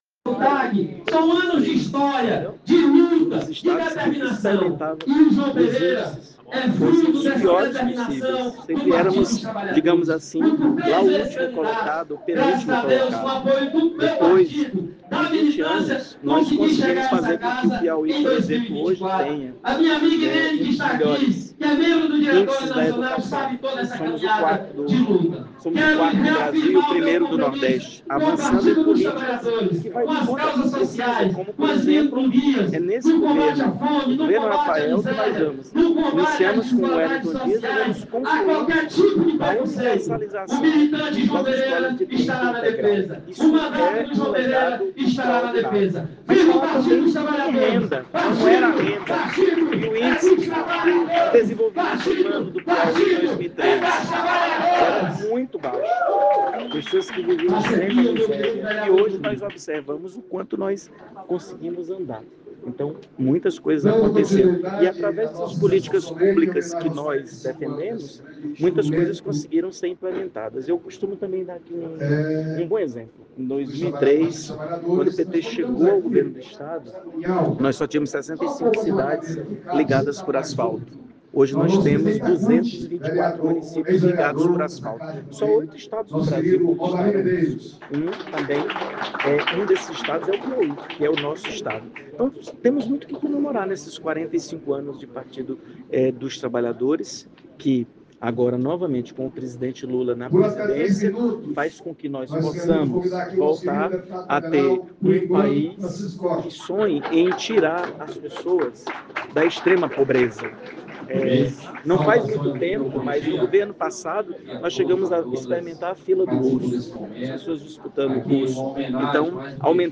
Fábio Novo participou de sessão solene na Câmara de Teresina para homenagear os 45 anos do partido